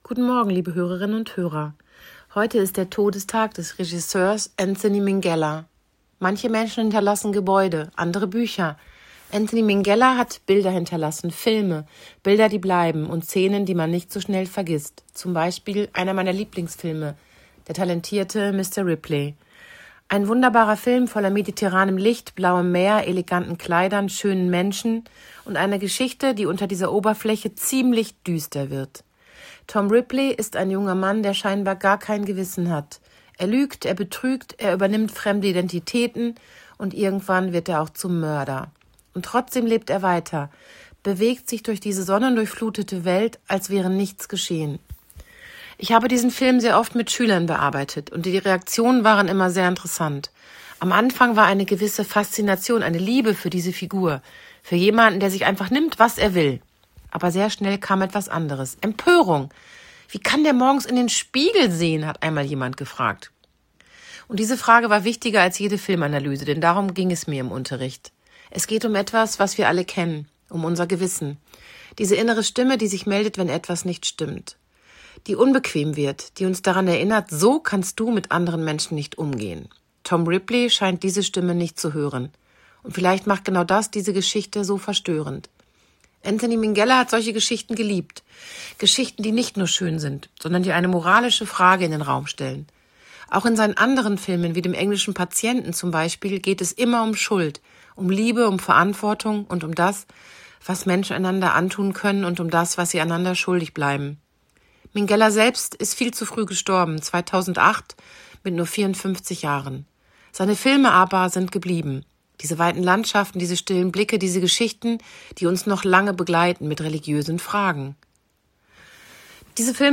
Hameln-Pyrmont: Radioandacht vom 18. März 2026